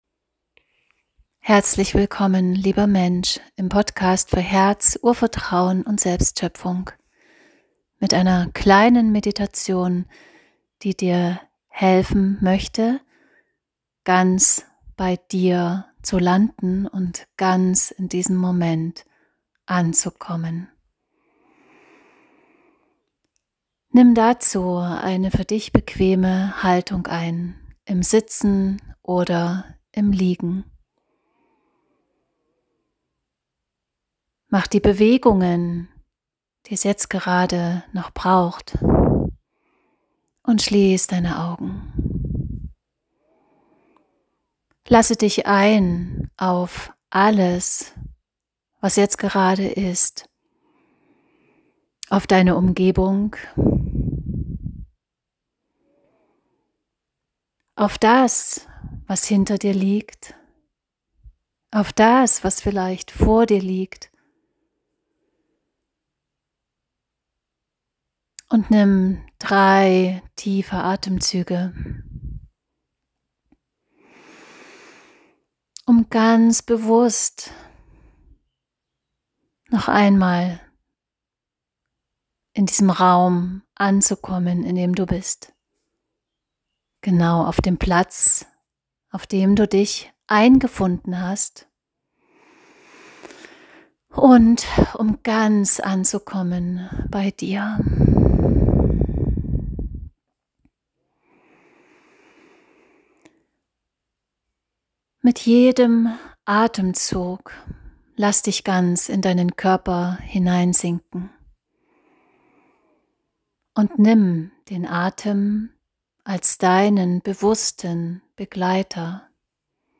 Atemmeditation_fuer_Ruhe_-_Frieden.m4a